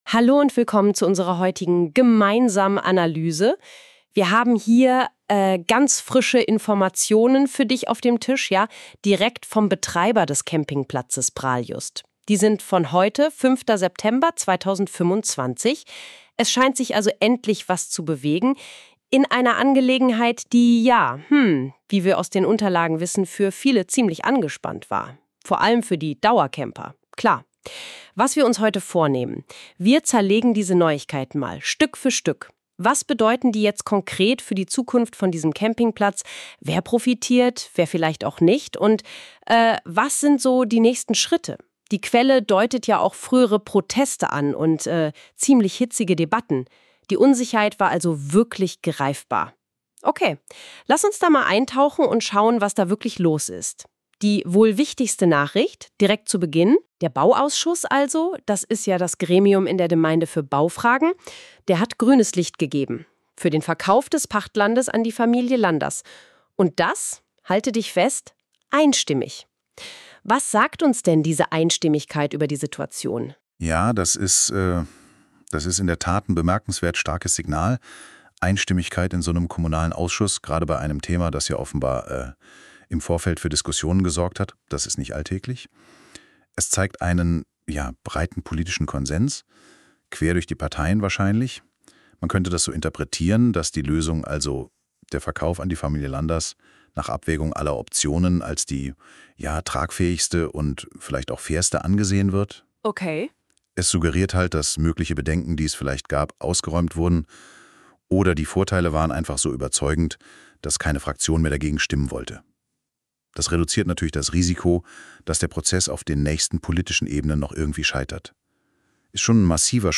Ein weiteres Update zu Prahljust, dieser Podcast wurde mit Hilfe von KI generiert.